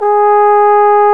Index of /90_sSampleCDs/Roland LCDP12 Solo Brass/BRS_Trombone/BRS_Tenor Bone 2